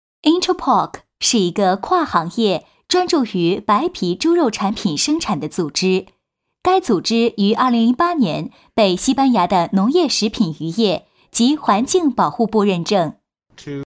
locutora china.  Doblaje de vídeo institucional